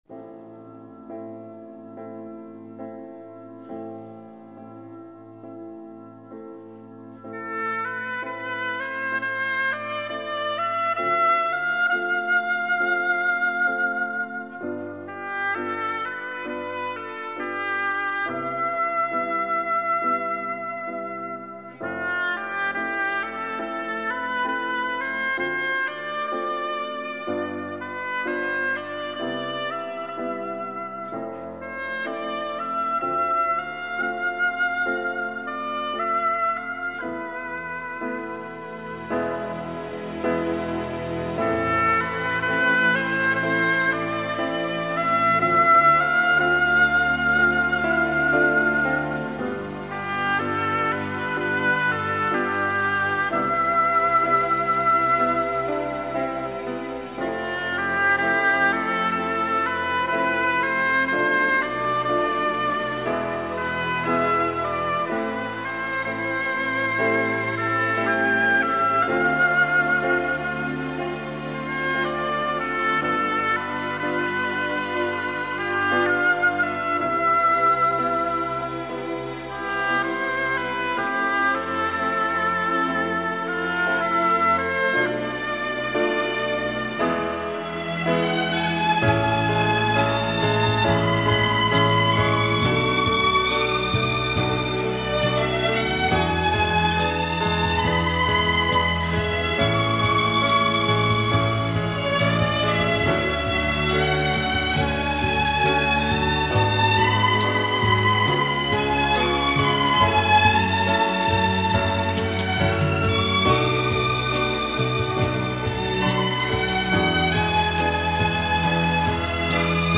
اثری  عاشقانه و فوق العاده زیبا
[نوع آهنگ: لایت]